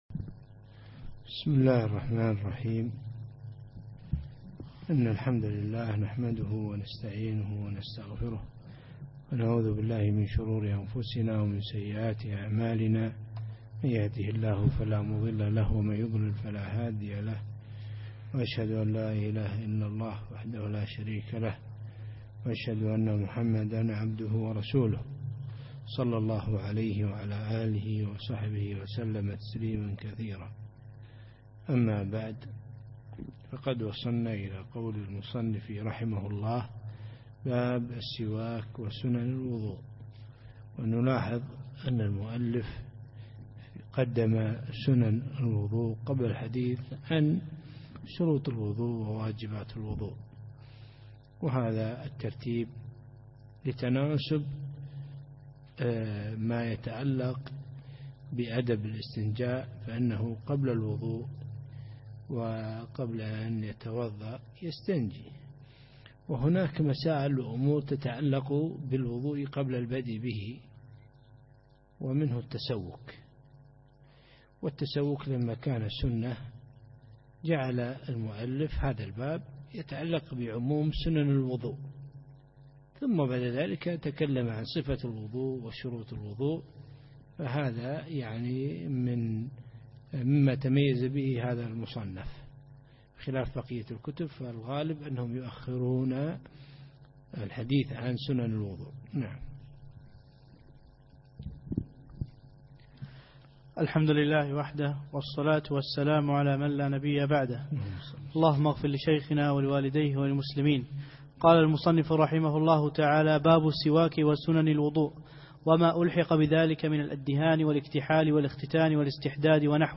الدرس الخامس: باب السواك وسنن الوضوء